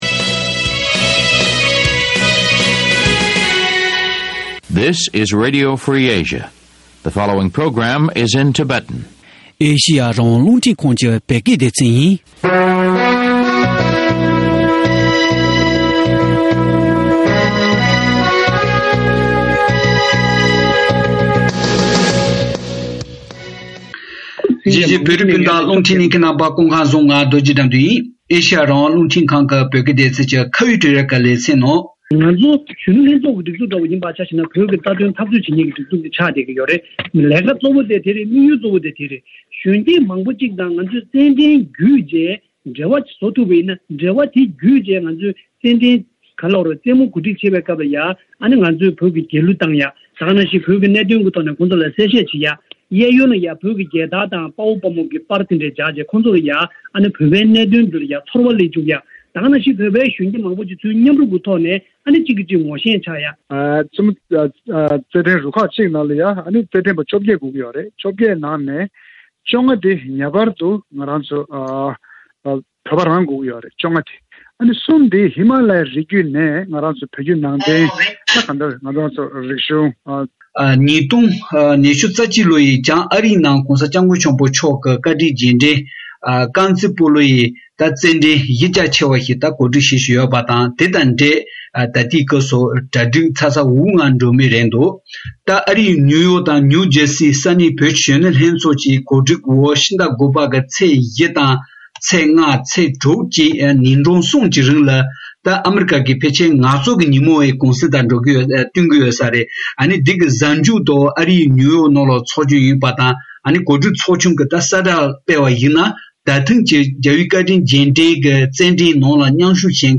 བཅའ་འདྲི་བྱེད་པ་གསན་རོགས་ཞུ།